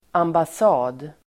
Uttal: [ambas'a:d]